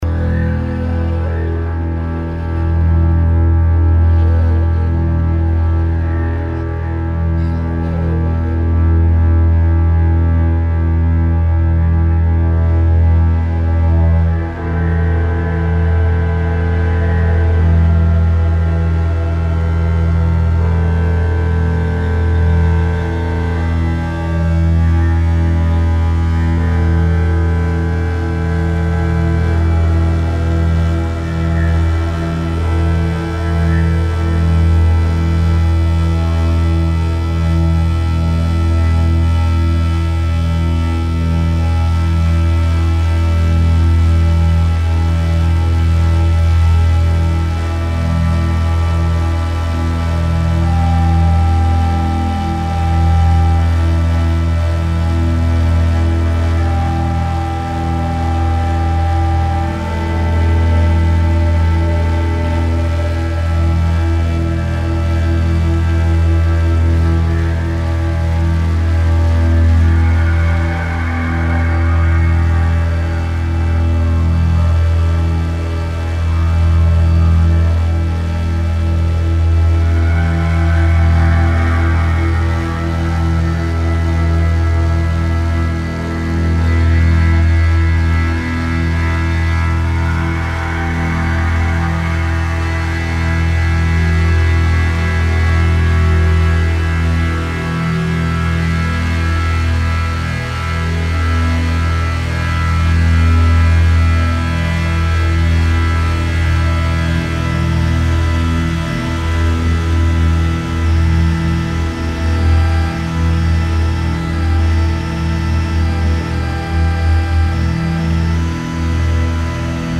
Mes inspirations se portent sur des morceaux de guitare, Calexico, Rodrigo y Gabriela et dans l’élan, je repense à une musique de Bowie qui beaucoup compté pour moi l’été 2014.
Au final, sans doute la bande musicale la plus planante que j’ai réalisée.